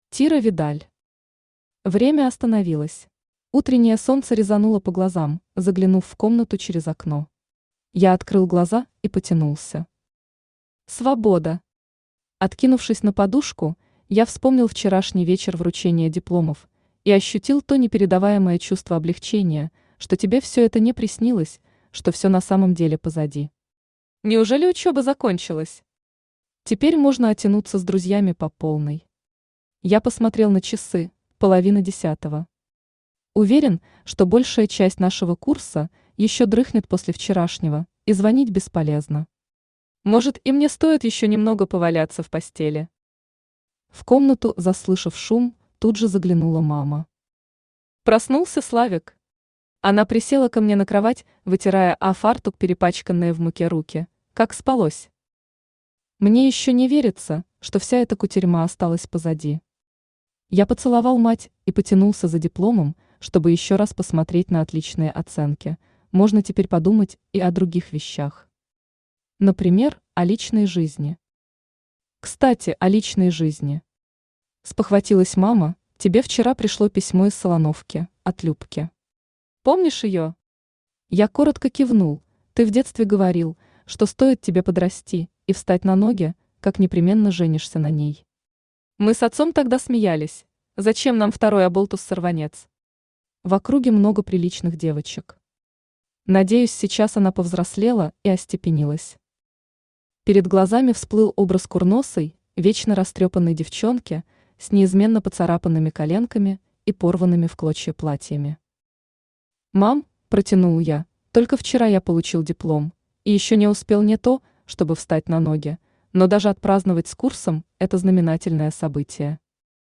Аудиокнига Время остановилось | Библиотека аудиокниг
Aудиокнига Время остановилось Автор Тира Видаль Читает аудиокнигу Авточтец ЛитРес.